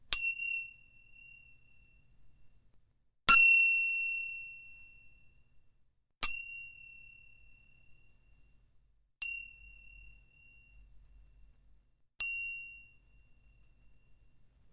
音效 " 自行车铃声
描述：金属自行车铃。